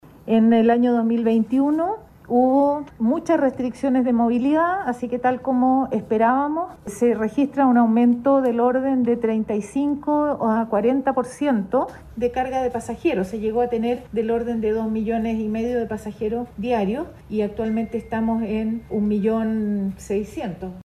Así lo afirmó la titular de la cartera de Transportes, Gloria Hutt.